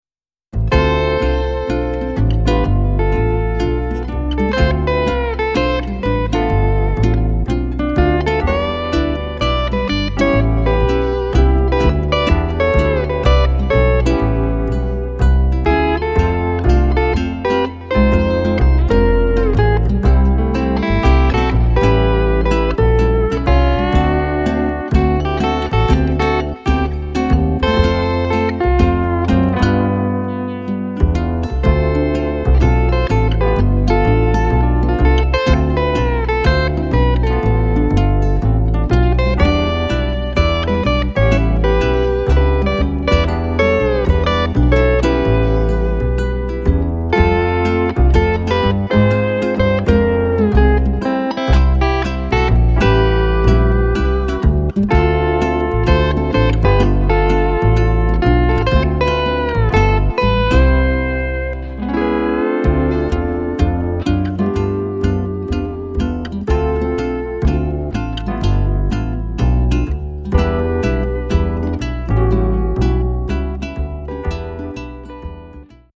Here’s a demo of a nice tune for Hawaiian Steel Guitar.